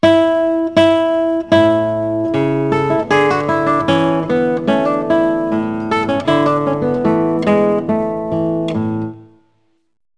guitclas.mp3